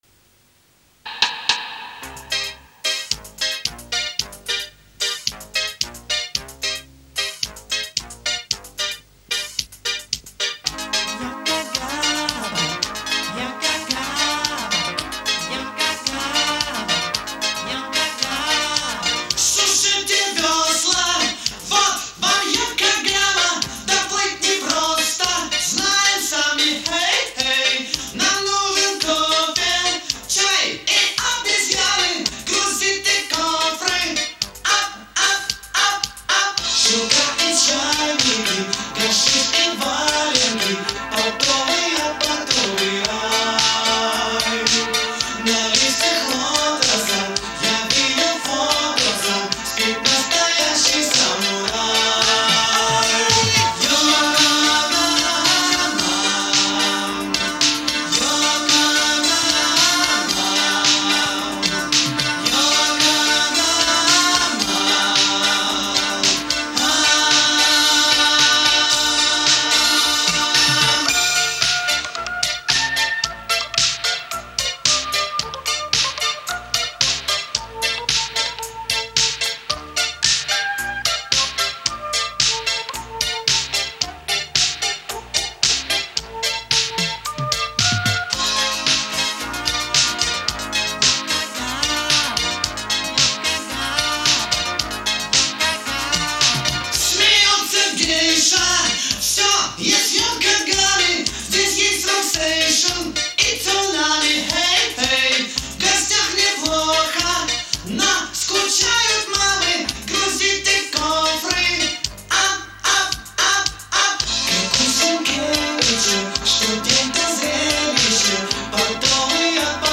На касете звучит намного лучше, чем оцифровка.